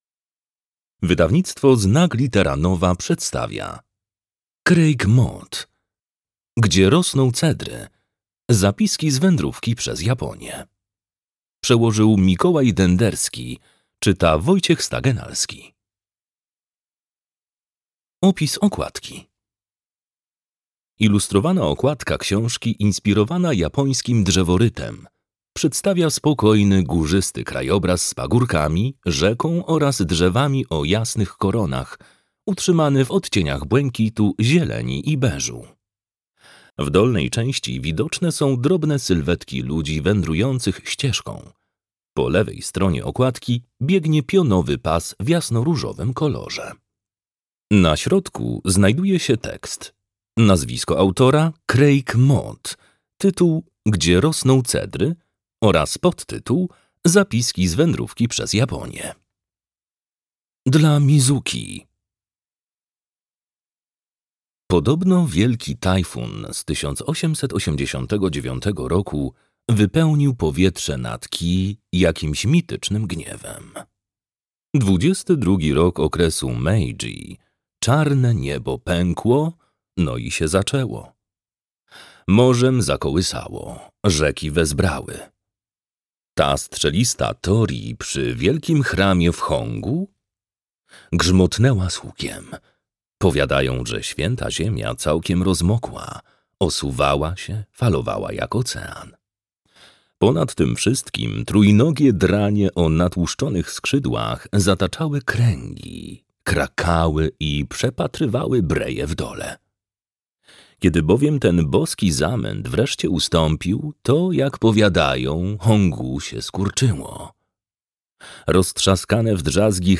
Gdzie rosną cedry. Zapiski z wędrówki przez Japonię - Craig Mod - audiobook + książka